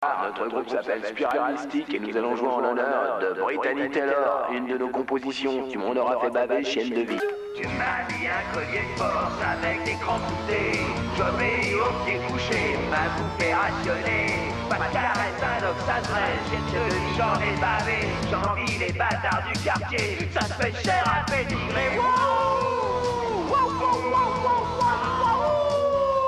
Vocal et guitares
Basse
Batterie